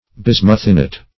Search Result for " bismuthinite" : The Collaborative International Dictionary of English v.0.48: Bismuthine \Bis"muth*ine\, Bismuthinite \Bis"muth*in*ite\, n. Native bismuth sulphide; -- sometimes called bismuthite.